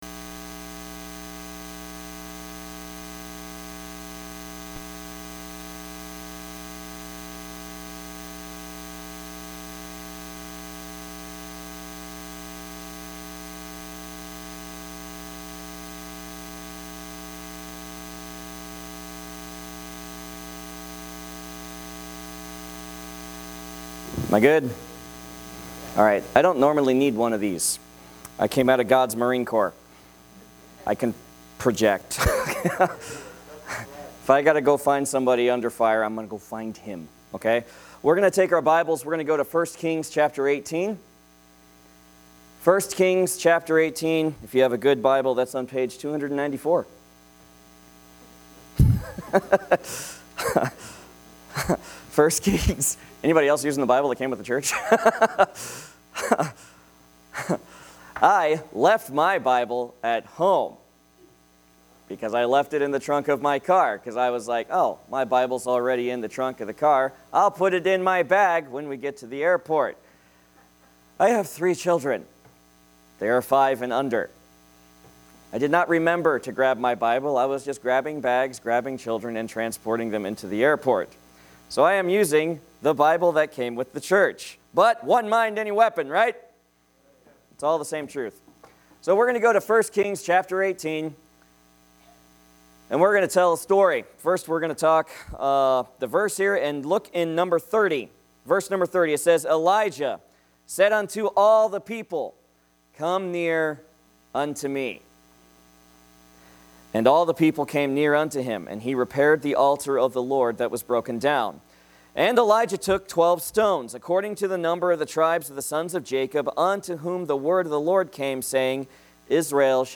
More Messages from Guest Speaker | Download Audio From Series: " Topical Messages " Non series messages Facebook Tweet Link Share Link Send Email